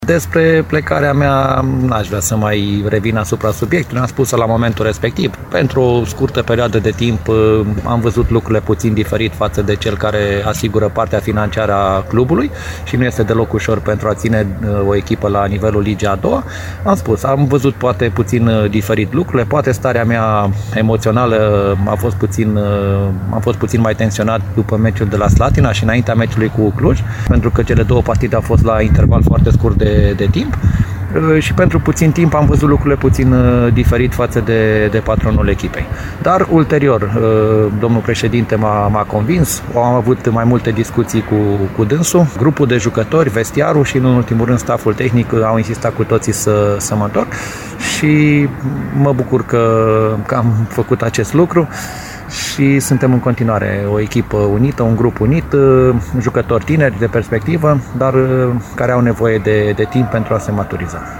Declarația